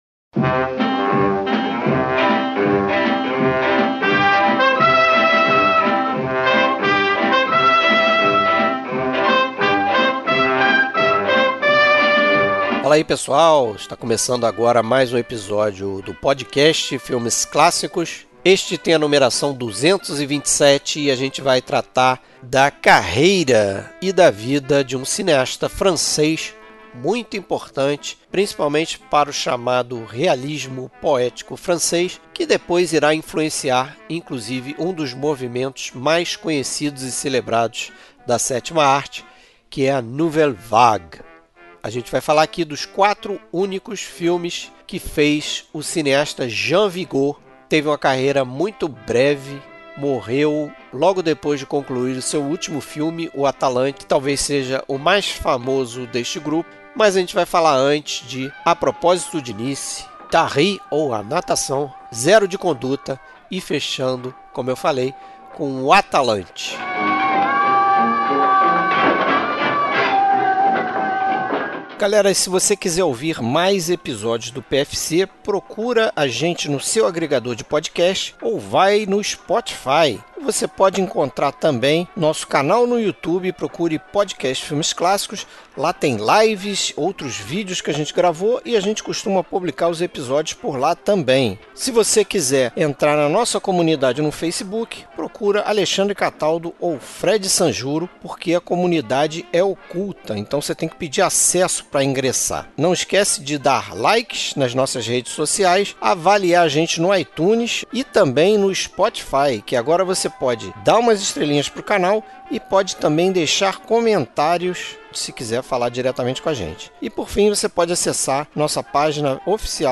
Neste episódio conversamos sobre toda a filmografia de Vigo: “A Propósito de Nice” ( À propos de Nice , 1930), “Taris ou A Natação” ( Taris, roi de l’eau , 1931), “Zero de Conduta” ( Zéro de conduite , 1933) e “O Atalante” ( L’Atalante , 1934). Trilha Sonora: trilha sonora de compositores que trabalharam nos filmes debatidos.